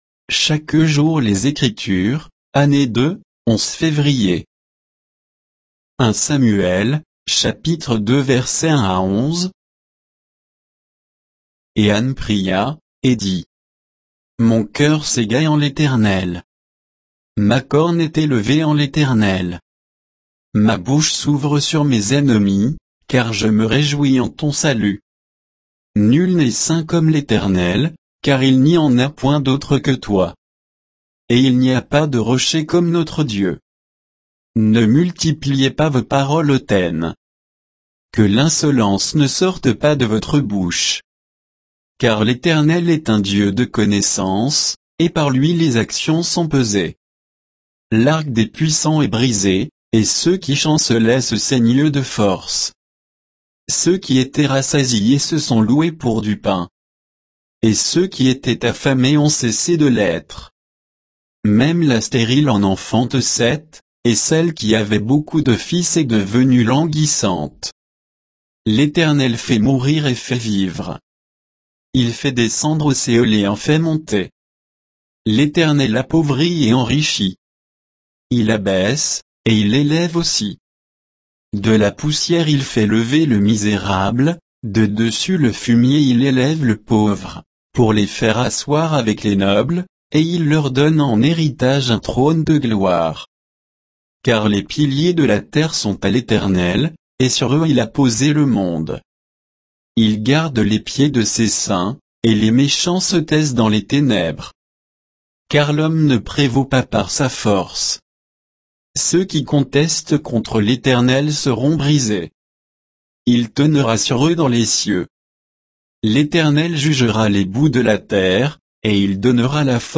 Méditation quoditienne de Chaque jour les Écritures sur 1 Samuel 2, 1 à 11